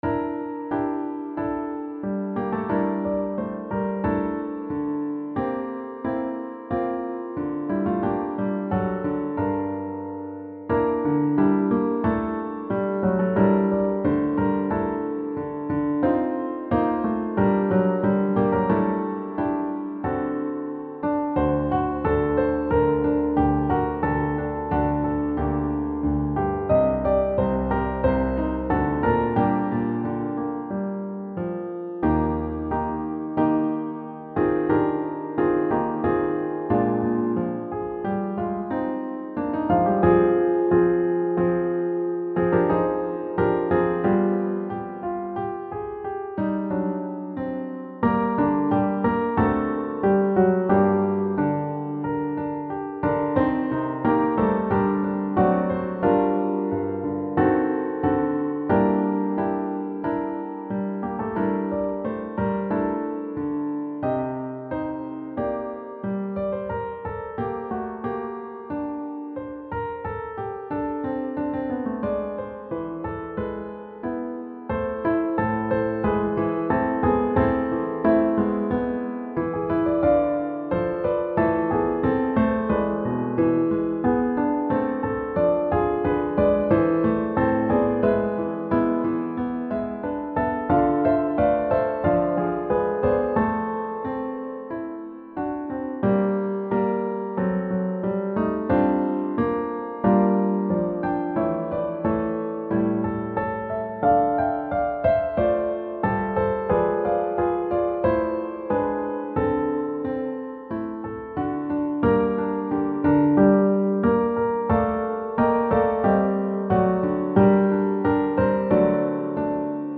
• Music Type: Choral
• Voicing: SATB
• Accompaniment: Piano